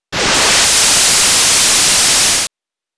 SFX
8 bits Elements